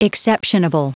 Prononciation du mot exceptionable en anglais (fichier audio)
Prononciation du mot : exceptionable